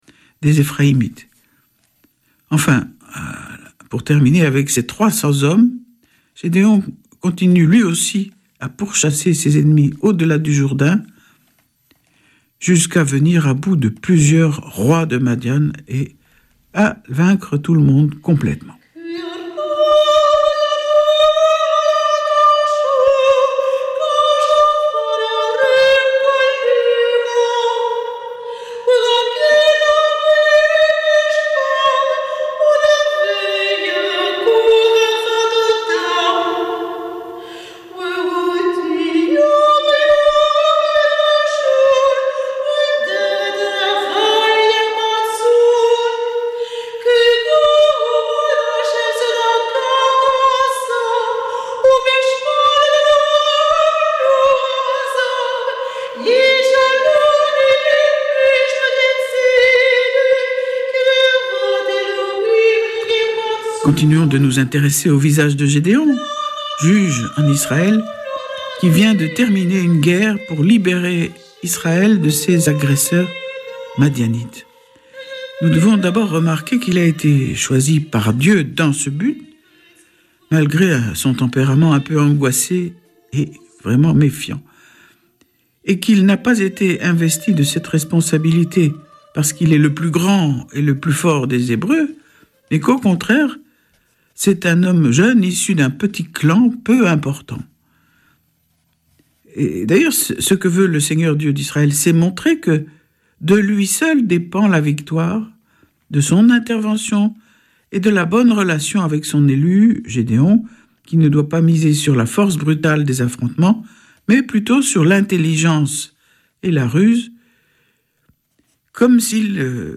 Vêpres de Saint Sernin du 10 août
Une émission présentée par Schola Saint Sernin Chanteurs